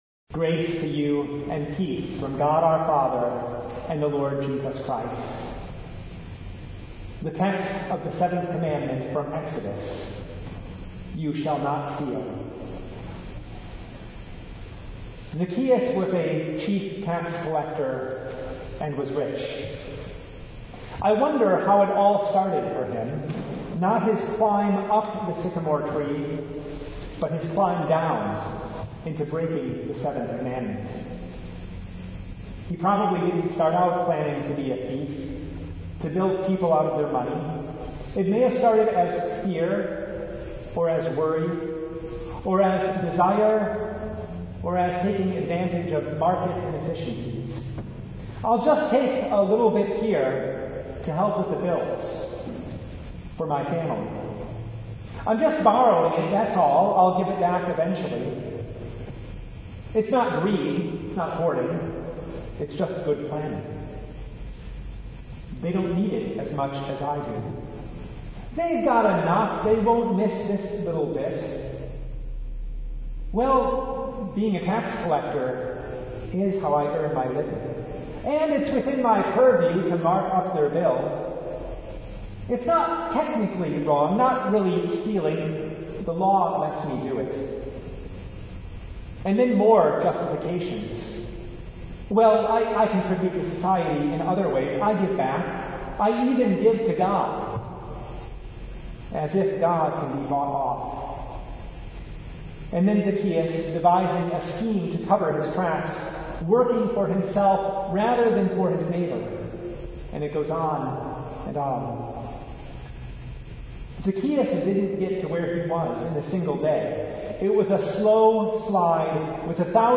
Service Type: Lent Midweek Vespers